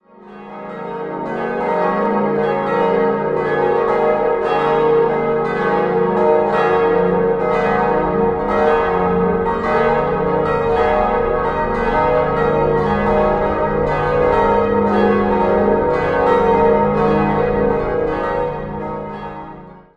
5-stimmiges ausgefülltes und erweitertes Es-Moll-Geläute: es'-ges'-as'-b'-des''
Evangelistenglocke
Agathaglocke
Marienglocke
Vitusglocke b' 382 kg 819 mm 1980 Glockengießerei Heidelberg
Deocarglocke des'' 302 kg 760 mm 1980 Glockengießerei Heidelberg